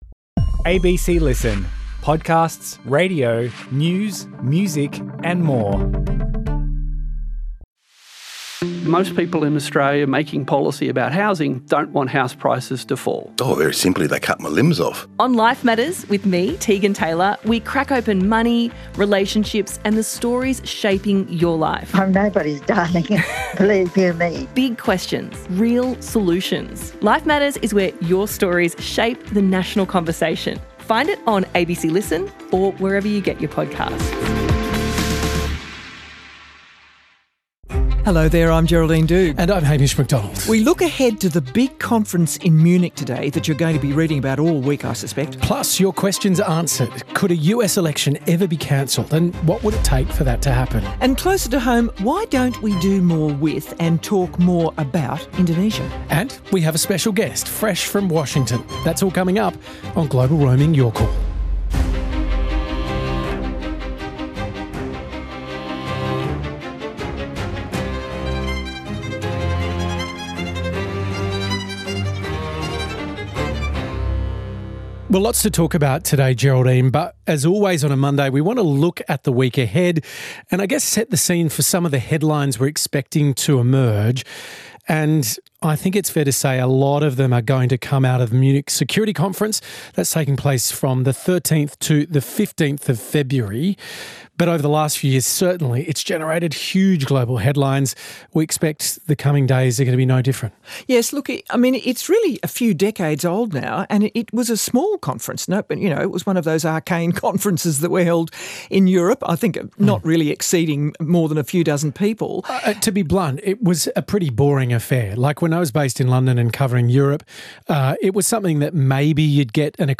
With the Munich Security Conference looming and Washington firmly in focus, Hamish Macdonald and Geraldine Doogue put your questions to the big issues shaping the global outlook; elections, defence, and Australia’s place in a shifting strategic landscape.